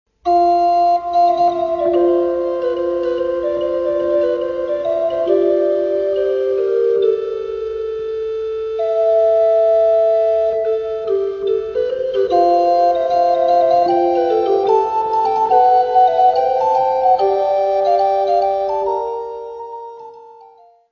Área:  Música Clássica